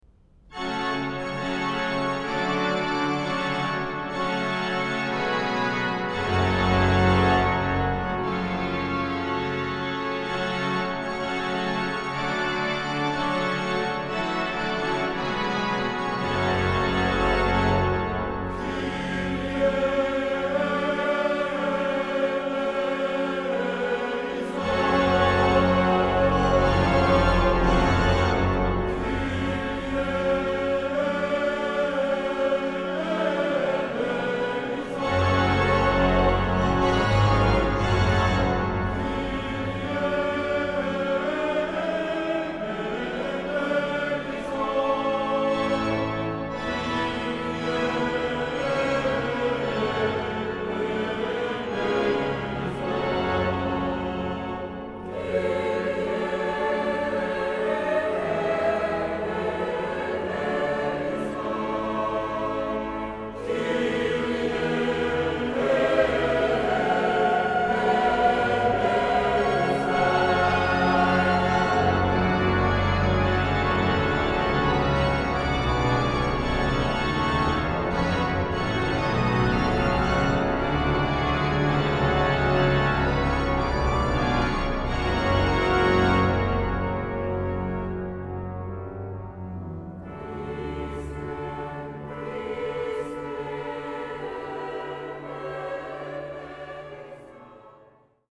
Grandes Orgues de Saint-Sulpice